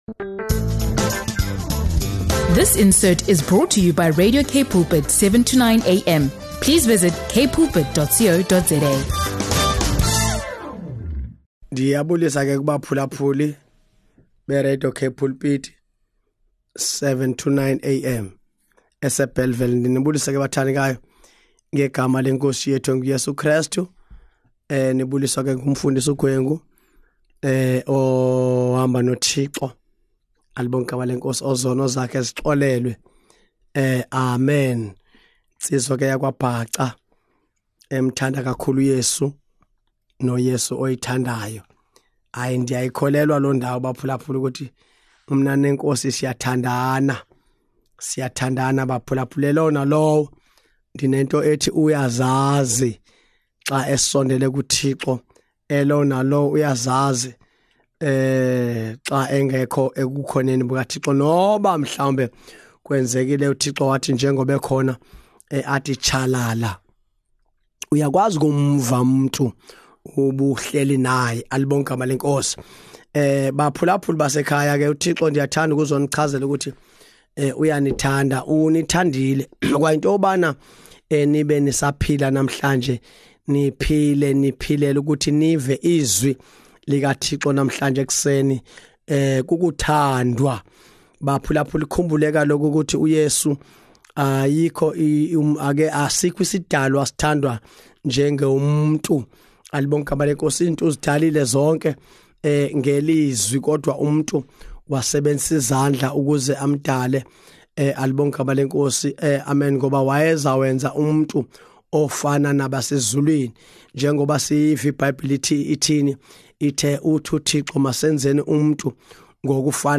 Tune in for an inspiring conversation that emphasizes the power of faith, community, and the ability to choose our own path.